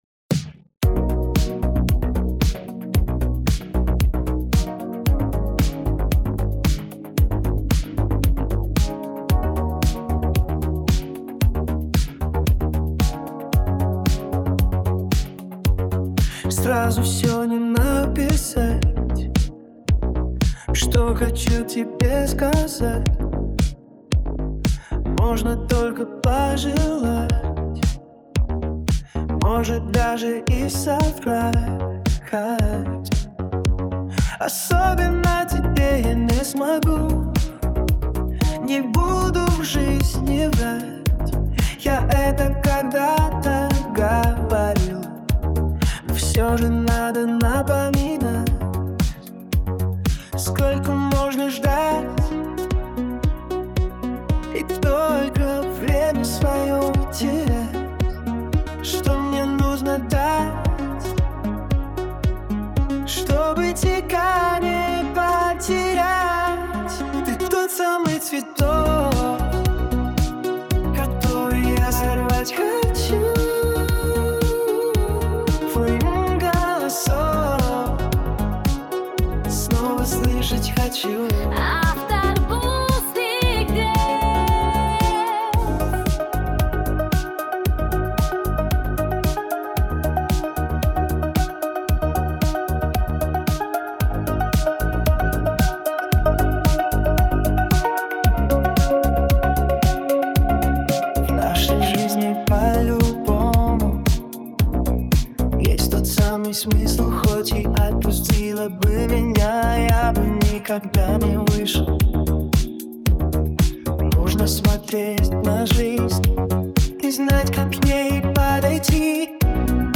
Прыклад музыкі і галасы створаны з дапамогай AI.